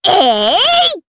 Miruru-Vox_Attack4_jp.wav